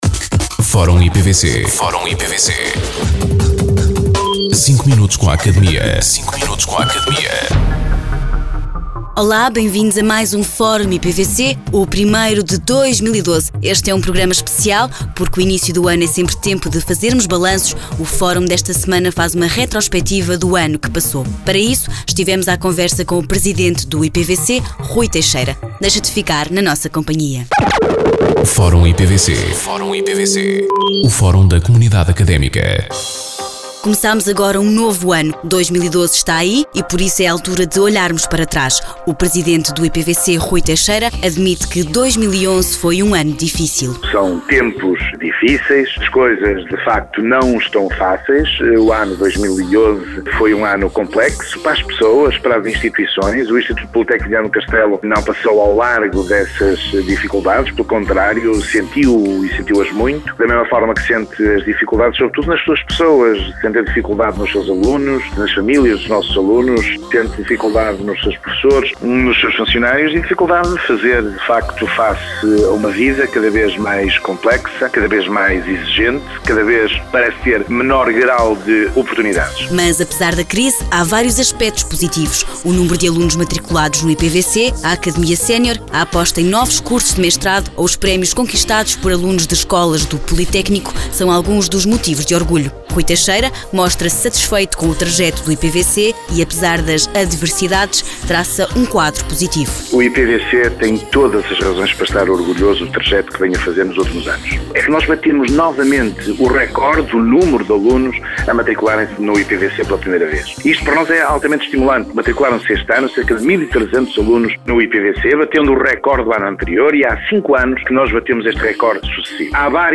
O Instituto Politécnico de Viana do Castelo [IPVC] tem mais um espaço radiofónico a ser transmitido, desta feita, na Rádio Caminha [RJC FM], em 106.2.
Entrevistados: